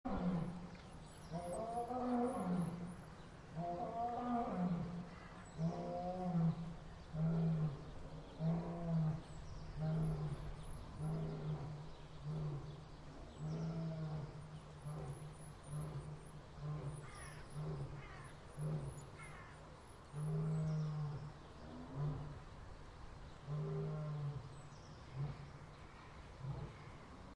Lion Téléchargement d'Effet Sonore
Lion Bouton sonore